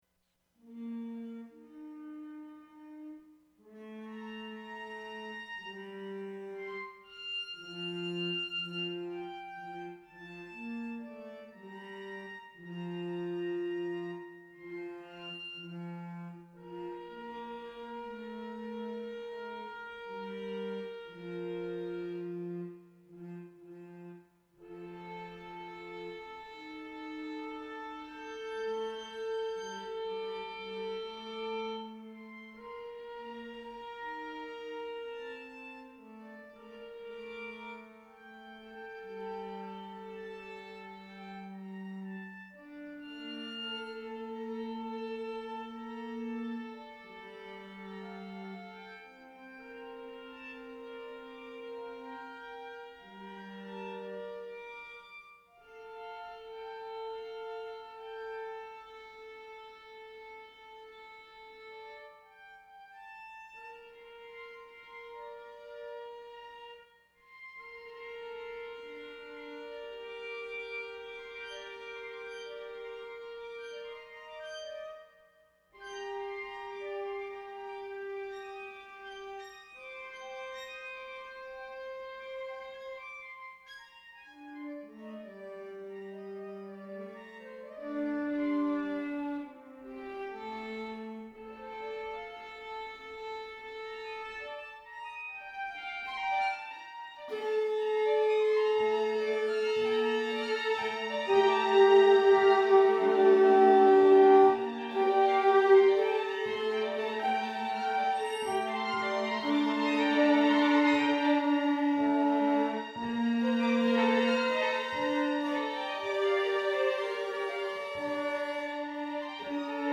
*This 13 minute example demonstrates a range of behaviours of the GitM system when under a simplified control system. User chose scale, rhythm style, performance technique (pizz, arco, etc.) pitch selection style, tempo and density. System made performance technique decisions based upon tempo and density as well. This example features a looser tuning model - this may sound "out of tune" to some listeners.
[string quartet] *This 13 minute example demonstrates a range of behaviours of the GitM system when under a simplified control system.